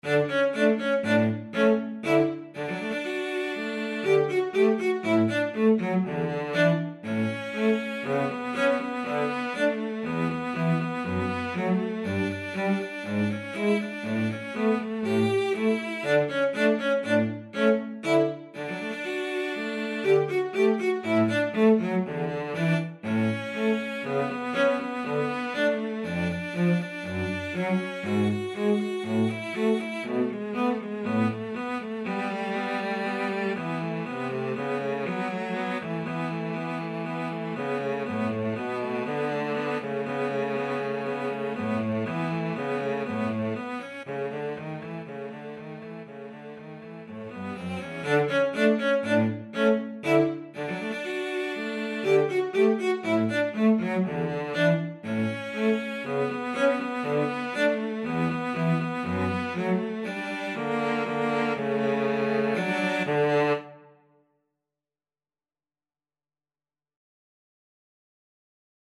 4/4 (View more 4/4 Music)
Allegro (View more music marked Allegro)
Cello Duet  (View more Intermediate Cello Duet Music)
Classical (View more Classical Cello Duet Music)